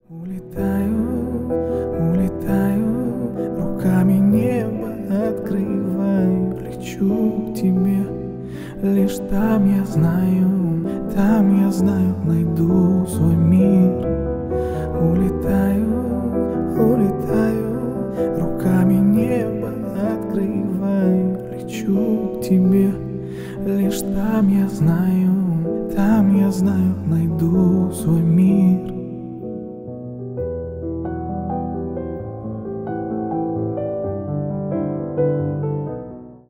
Поп Музыка
грустные # спокойные # кавер